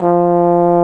Index of /90_sSampleCDs/Roland L-CDX-03 Disk 2/BRS_Trombone/BRS_Tenor Bone 3